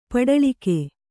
♪ paḍaḷike